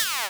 cacoplushie_despawn.wav